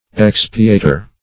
expiator - definition of expiator - synonyms, pronunciation, spelling from Free Dictionary Search Result for " expiator" : The Collaborative International Dictionary of English v.0.48: Expiator \Ex"pi*a`tor\, n. [L.] One who makes expiation or atonement.